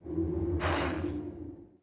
Index of /App/sound/monster/spite_ghost
walk_act_1.wav